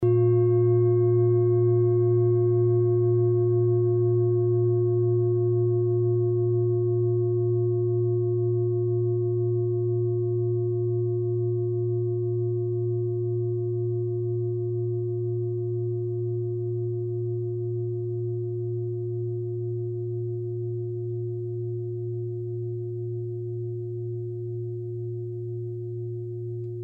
Klangschale TIBET Nr.30
Sie ist neu und ist gezielt nach altem 7-Metalle-Rezept in Handarbeit gezogen und gehämmert worden.
(Ermittelt mit dem Filzklöppel)
klangschale-tibet-30.mp3